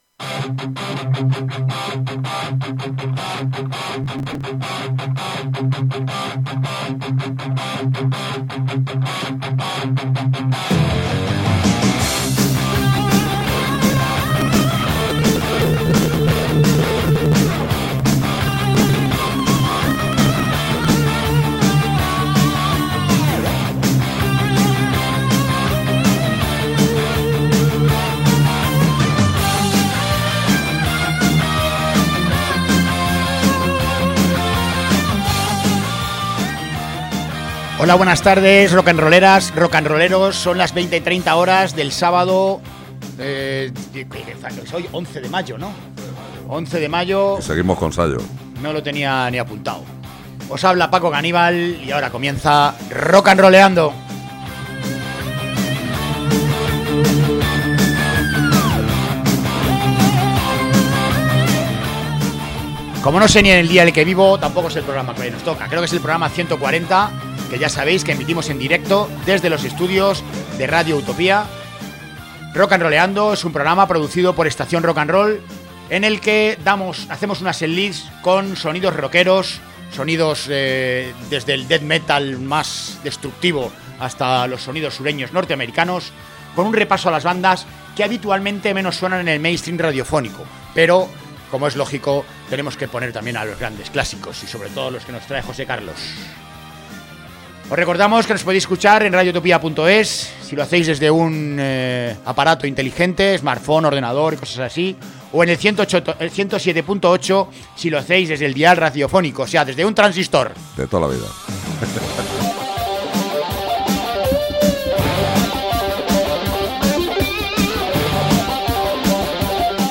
Ásperos riffs de guitarra, voces convincentes y una columna vertebral de ritmo contundente.
Una maravilla cada vez que nos presenta un tema, todos cargados de riffs ultra-contagiosos. una nueva forma de hacer hard rock, llena de lo mejor de Zeppelin y AC/DC , con un toque eminentemente sureño.